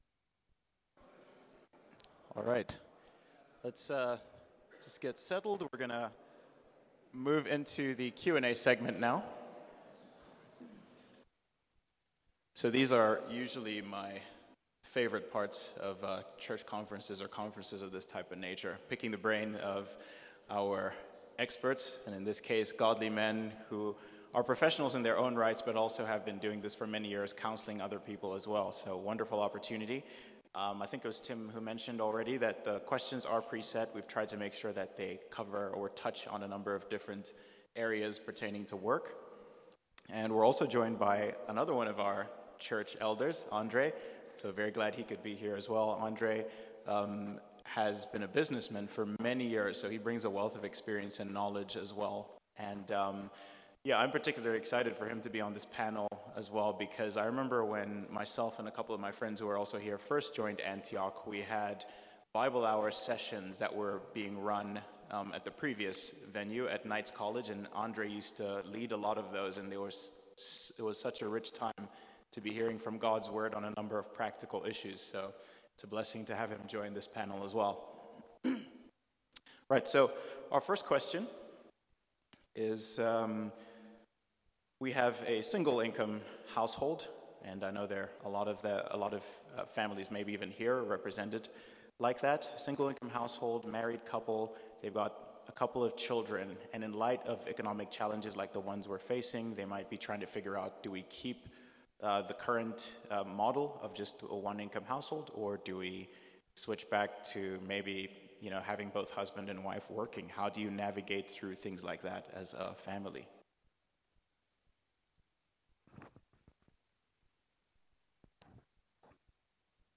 Audio volume fluctuates…